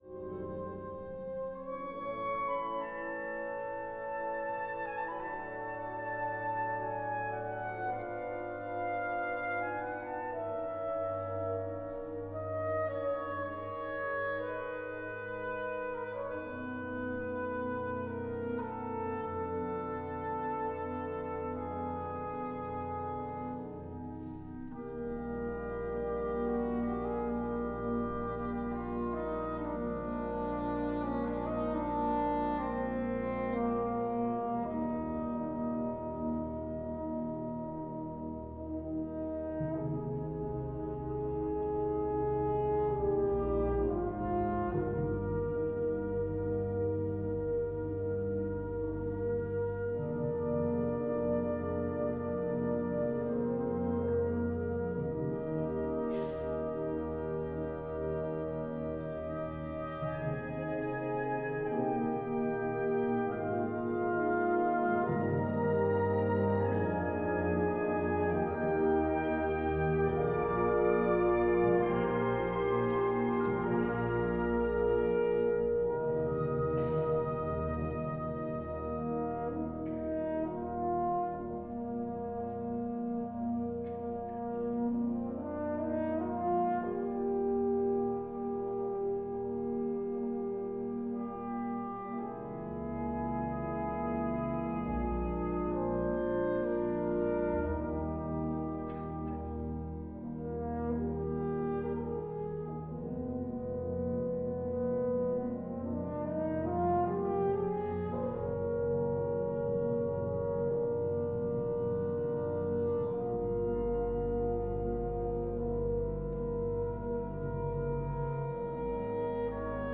Ein Heldenleben, sopraansax solo, 9 mei 2024
Heldenleben-sopraansax.mp3